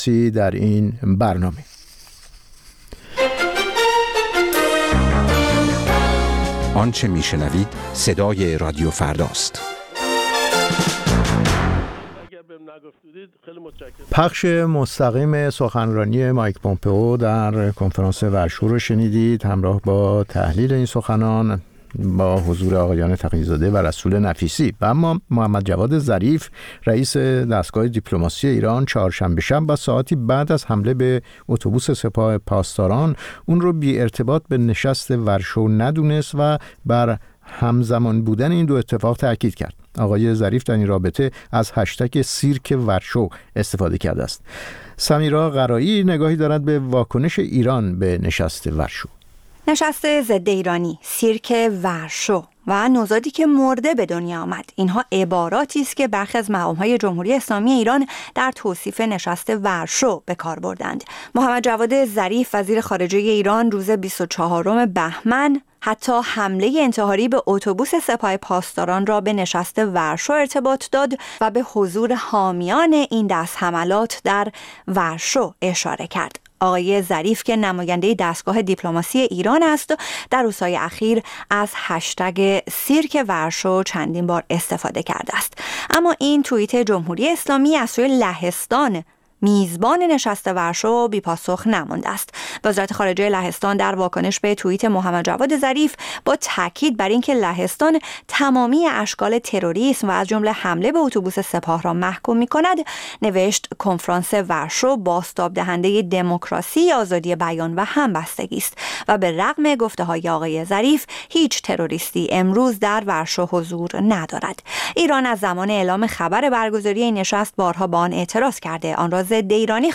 تابو را هر هفته پنج‌شنبه‌ها ساعت هفت‌ونیم بعدازظهر به وقت ایران از رادیوفردا بشنوید.